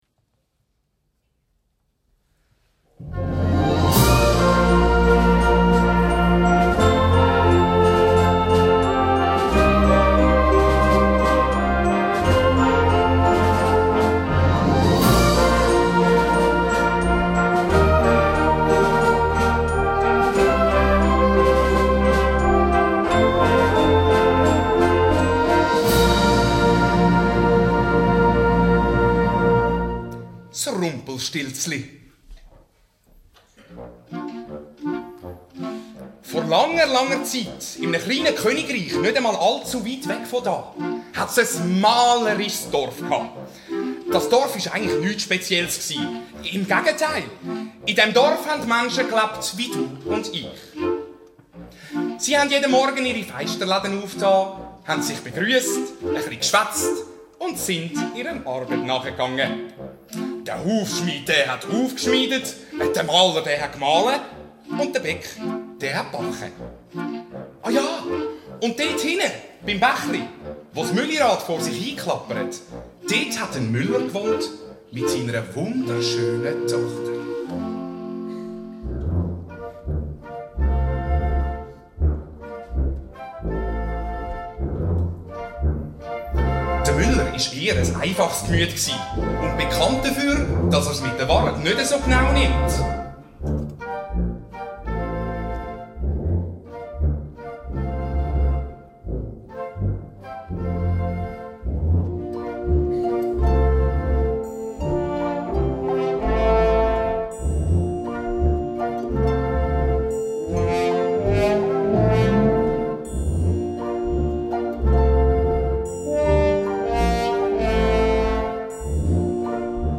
Gattung: für Erzähler und Blasorchester
Besetzung: Blasorchester